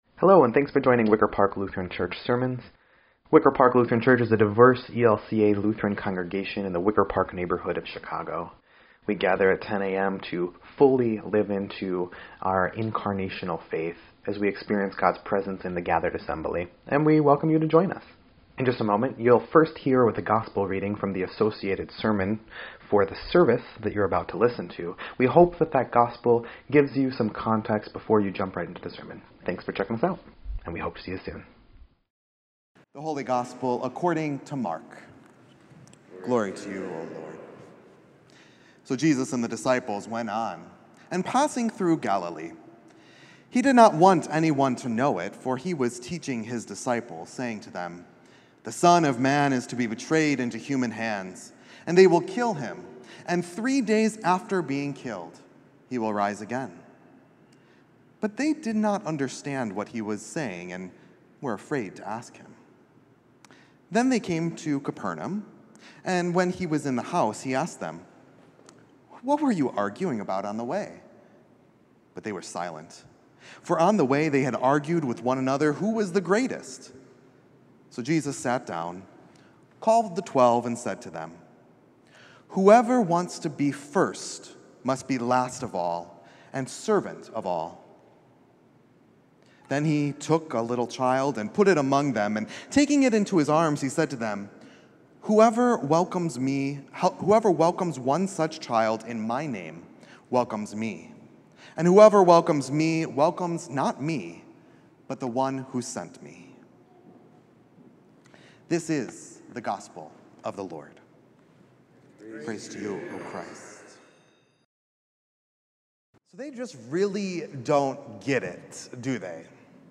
9.22.24-Sermon_EDIT.mp3